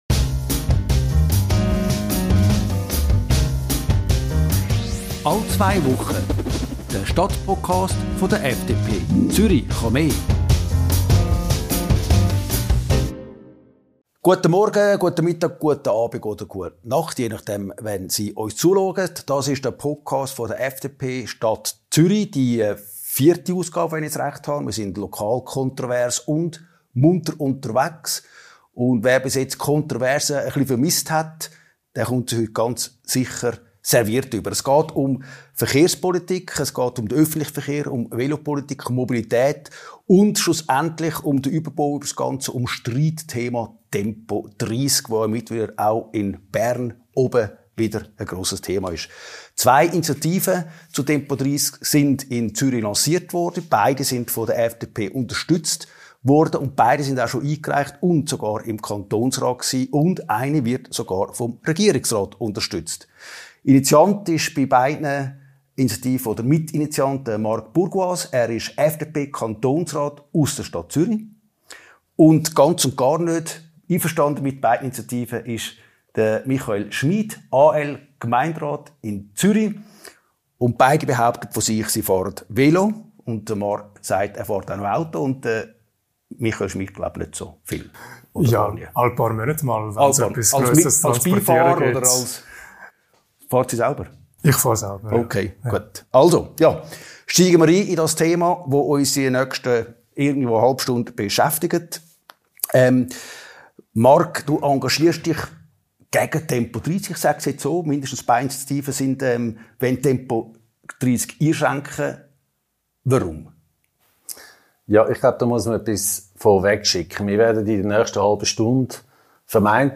Das muntere Streitgespräch führt über Veloschnellrouten, Parkplatzkampf und Strassenraum in die tiefen der Zürcher Verkehrspolitik. Spoiler: Zum Schluss finden die beiden Politiker doch noch Gemeinsamkeiten.